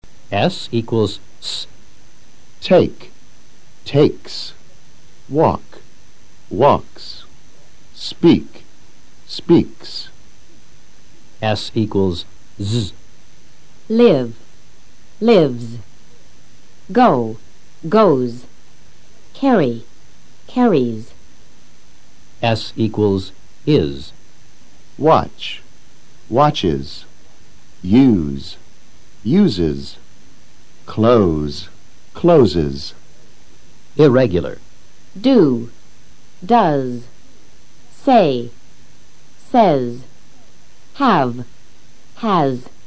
Escucha la pronunciación de la -s en estos verbos en tercera persona del singular (HE, SHE, IT) del PRESENTE SIMPLE.
s  =  / S /
s  =  / Z /
s  =  / IZ /